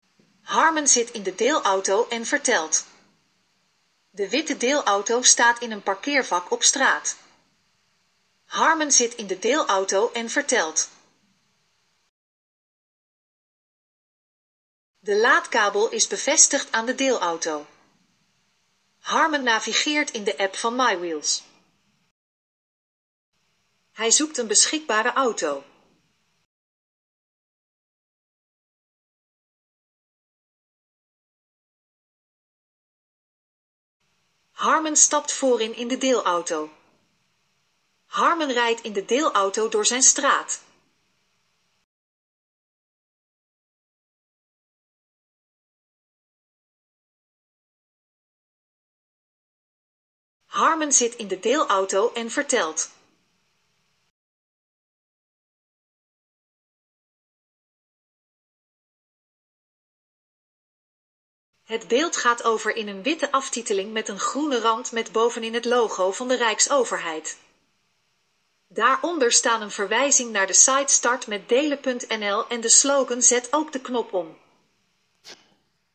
Audiobeschrijving